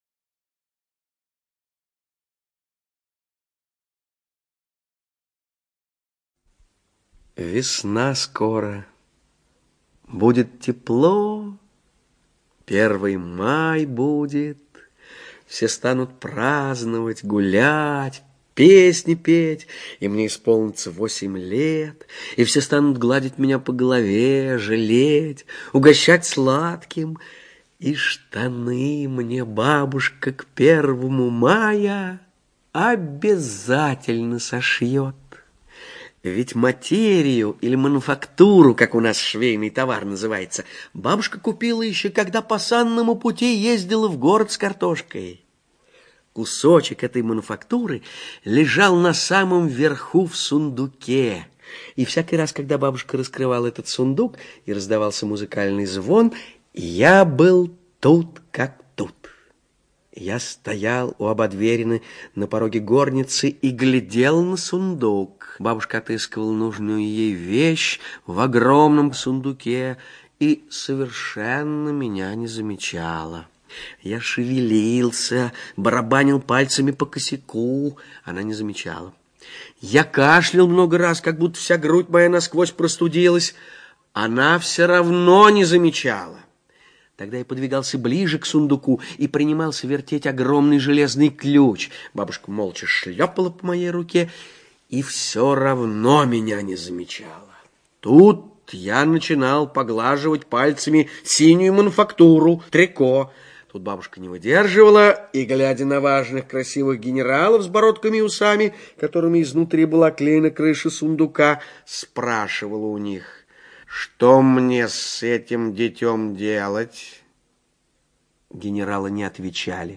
ЧитаетТабаков О.